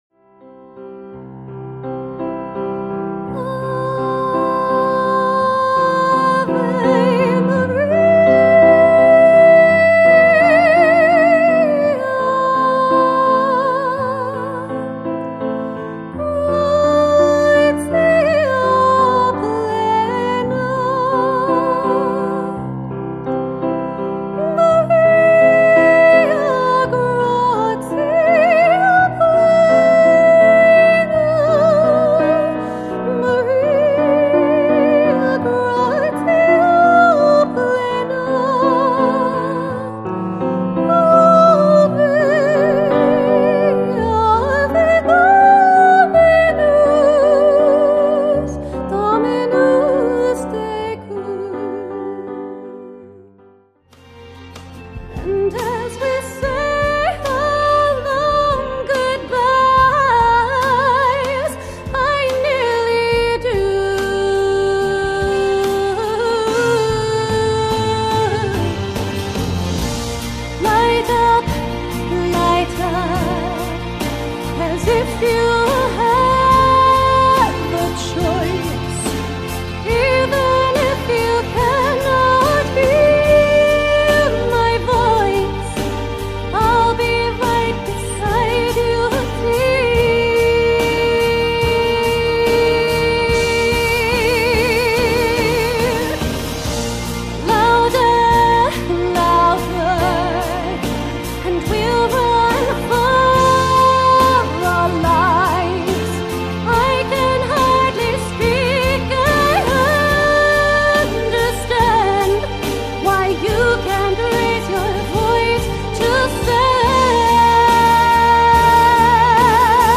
Solo Classical and Contemporary Singer For Hire
• Classical Soprano vocalist who has performed globally
• Ethereal folk singing
Female Vocals, Backing tracks